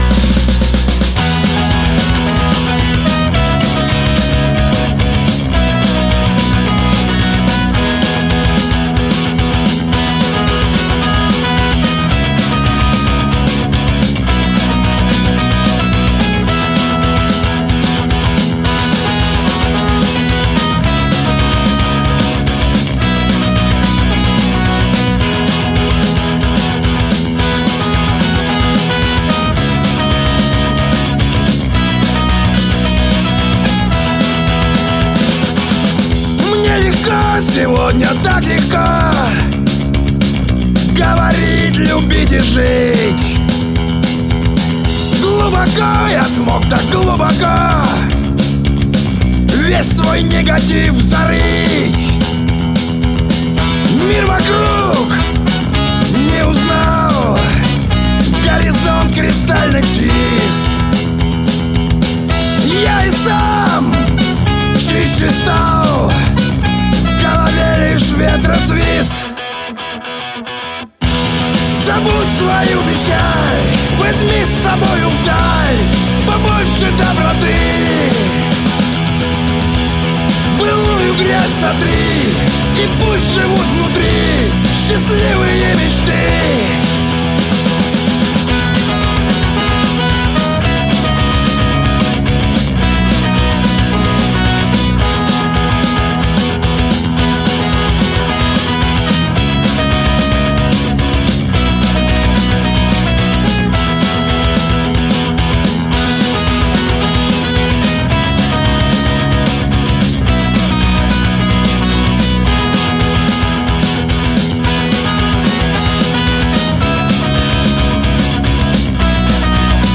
青春时尚